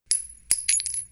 shellDrop6.wav